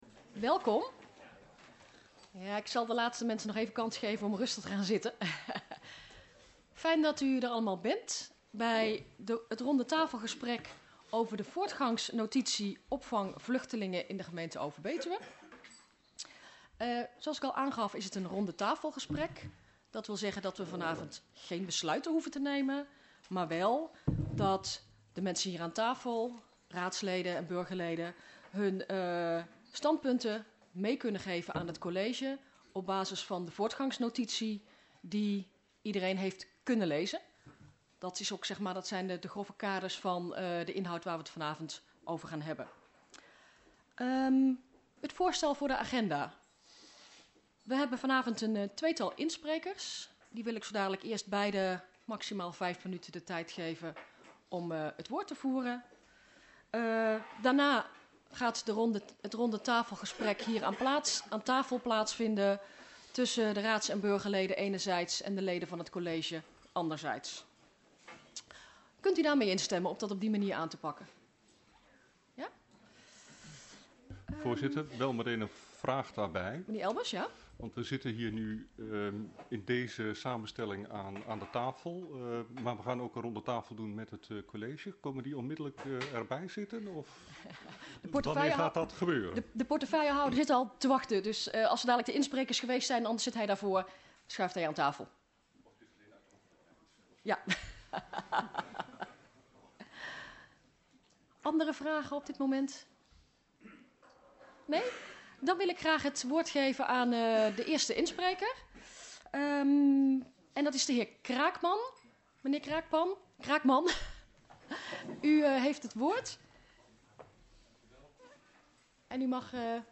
Locatie Hal, gemeentehuis Elst Voorzitter mevr. J. Rouwenhorst Toelichting RTGC voortgangsnotitie opvang vluchtelingen.